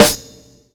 Snares
sdfgLive_snr.wav